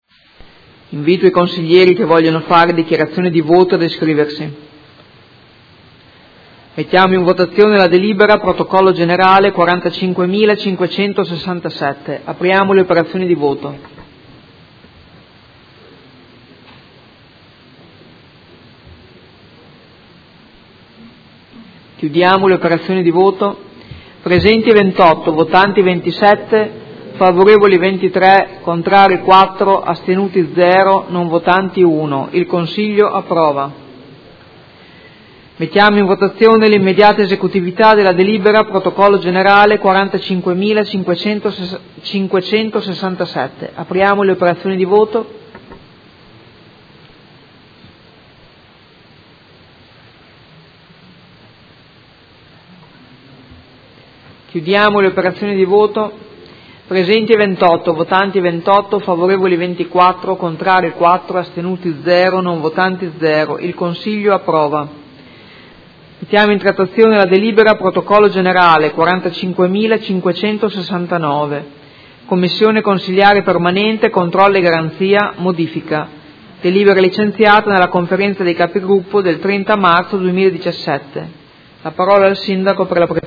Presidente — Sito Audio Consiglio Comunale